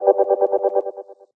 whoosh_longer_chopper_5.ogg